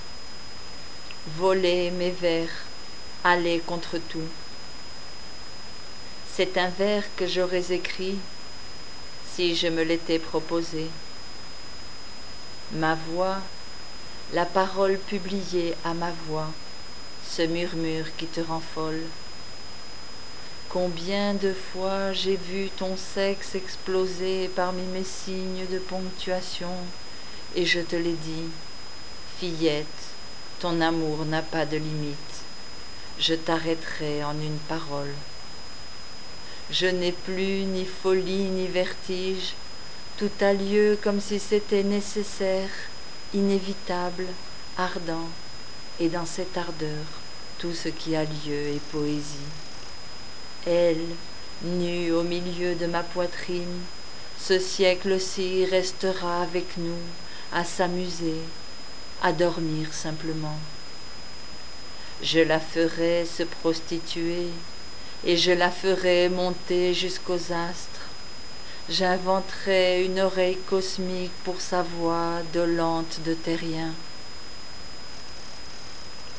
récite